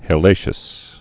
(hĕ-lāshəs)